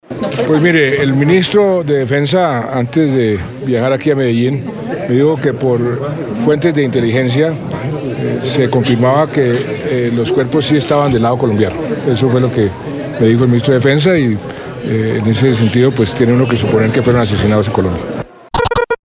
En una breve declaración a la prensa, el mandatario señaló que esta información fue confirmada por el ministro de Defensa, en base a "fuentes de inteligencia".